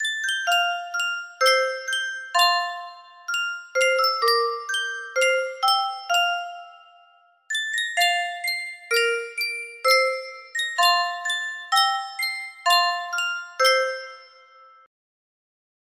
Sankyo Music Box - The Good Shepherd DUS music box melody
Sankyo Music Box - The Good Shepherd DUS
Full range 60